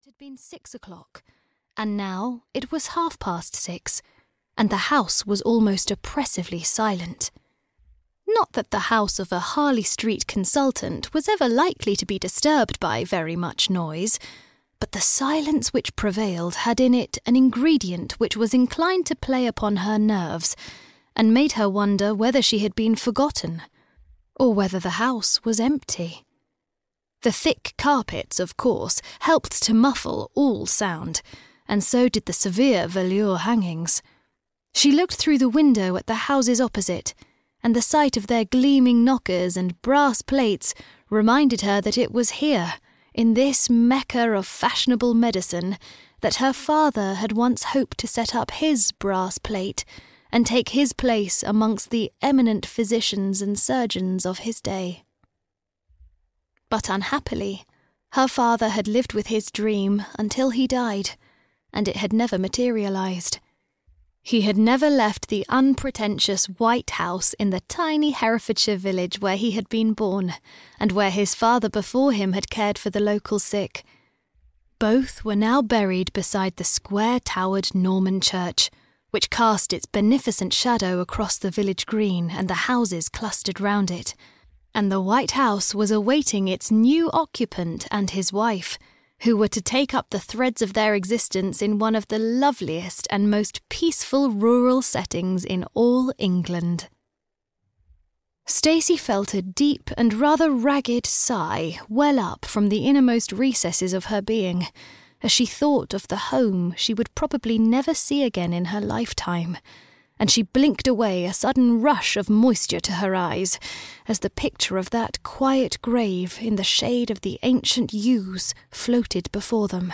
Marry a Stranger (EN) audiokniha
Ukázka z knihy